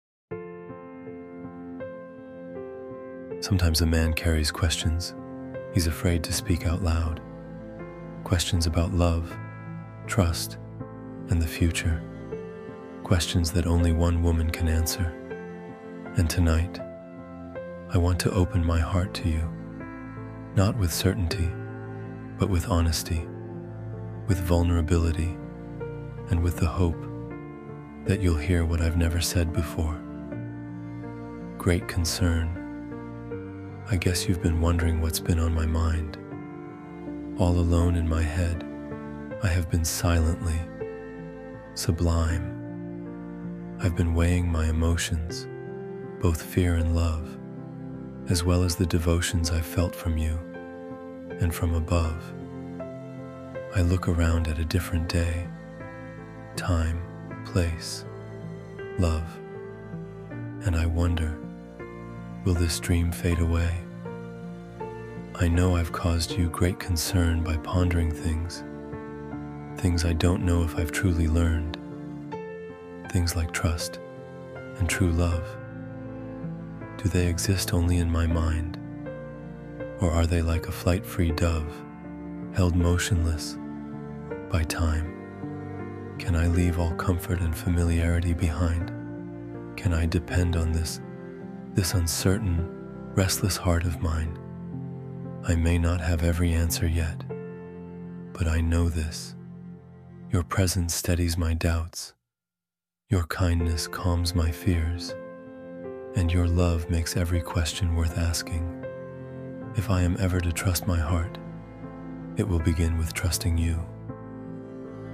great-concern-deep-love-poem-male-narration-for-her.mp3.mp3